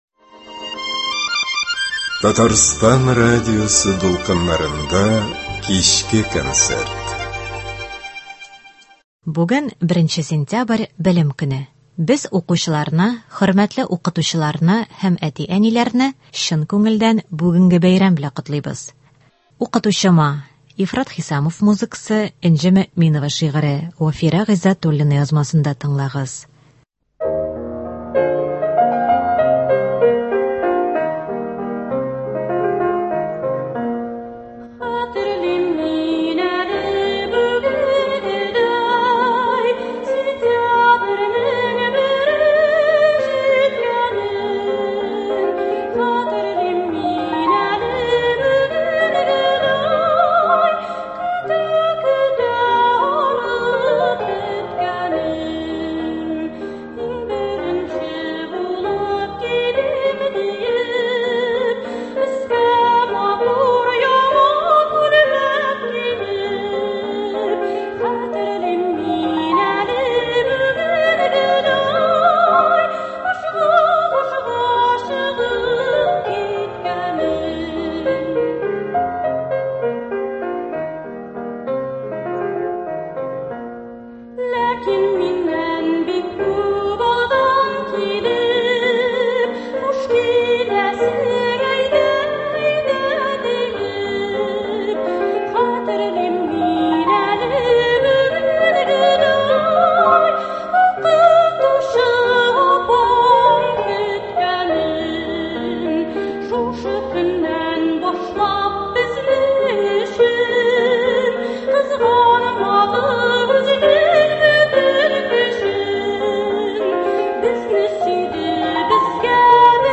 Белем көненә багышланган концерт.